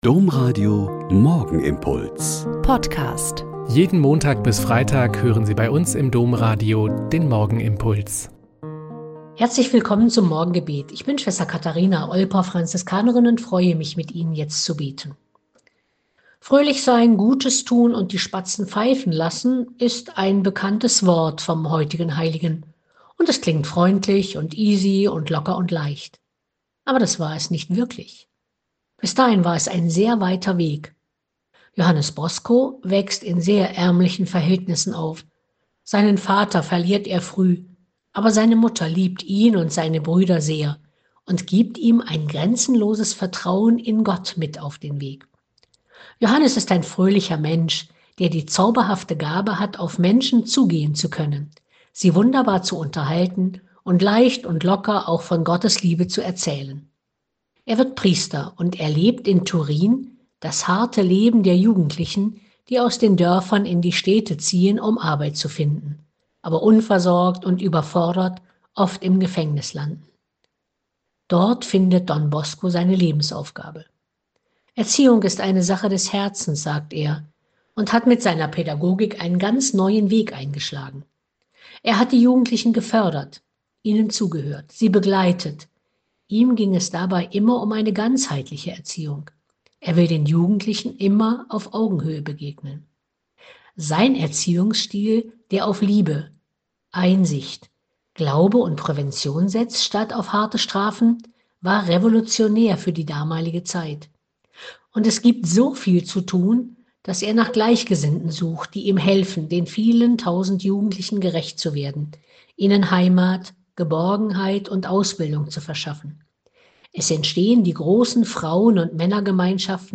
Mk 46, 45-62 - Gespräch